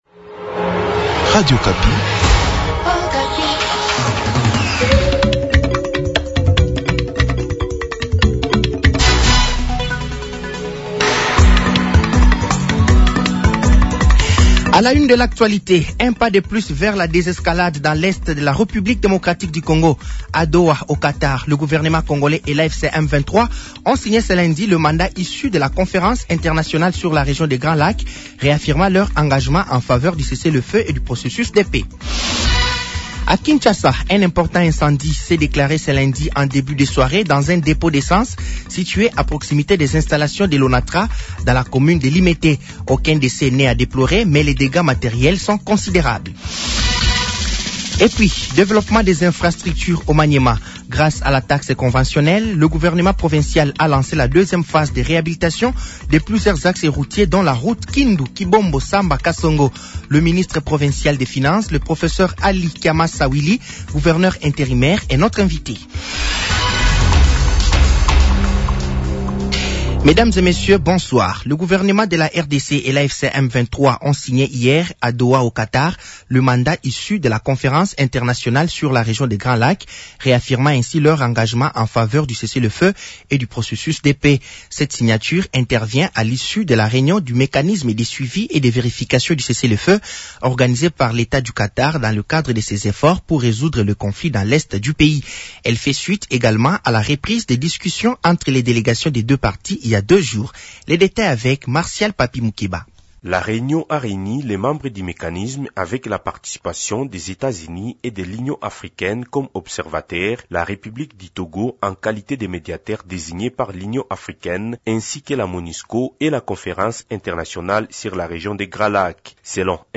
Journal français de 18h de ce mardi 3 février 2026 03 février 2026